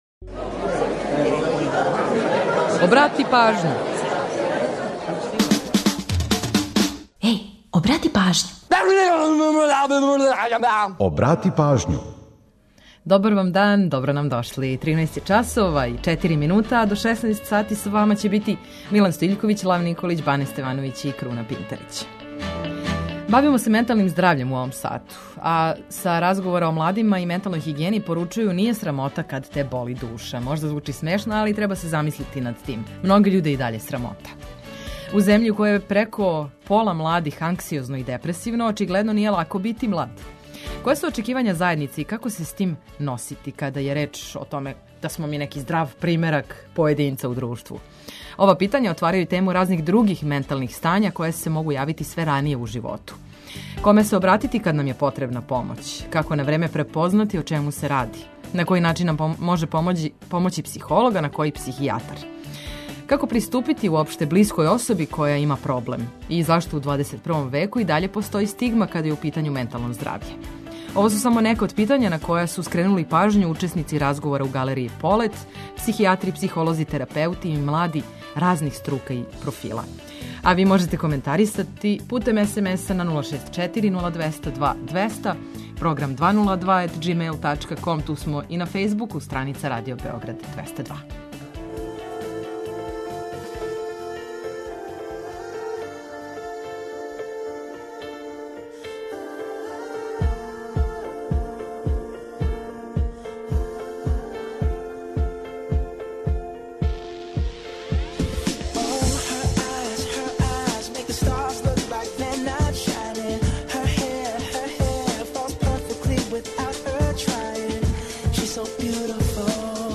Ово су само нека од питања на која су скренули пажњу учесници разговора у галерији Полет , психијатри, психолози, терапеути и млади разних струка и профила.